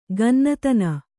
♪ gannatana